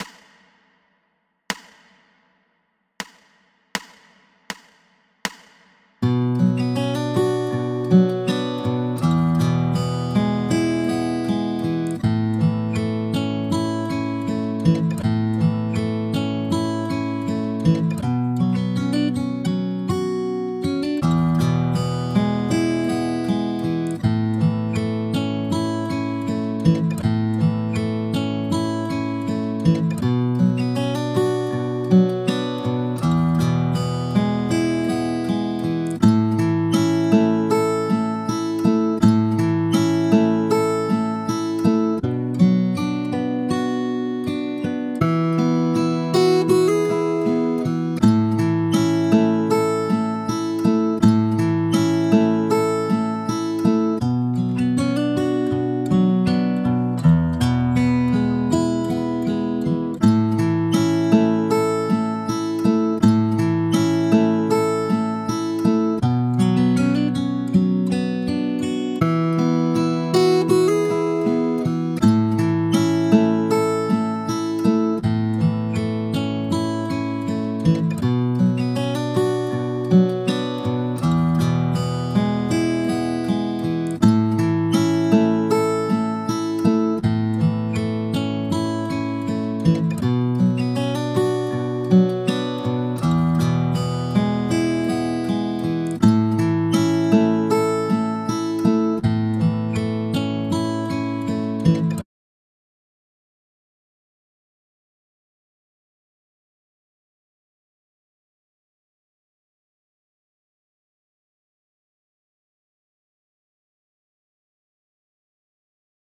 Here are some fun and creative ways to play though a 2 5 1 chord progression using some novel ideas. From the "double sugar foot" Lloyd Mainesl minor chord move to the Franklin Pedal, with various other positions, pedals and knee levers.